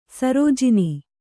♪ sarōjini